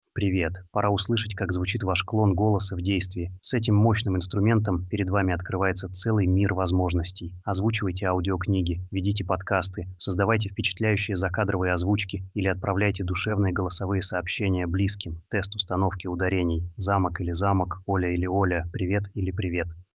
Мы собрали оригинальную запись и три клона, чтобы вы могли сами послушать и сравнить качество.
• ElevenLabs — клон голоса
ELVENLABS_V2_VOICECLONE.mp3